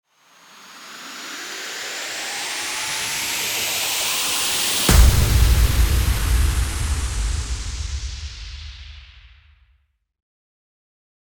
FX-1589-RISNG-IMPACT
FX-1589-RISNG-IMPACT.mp3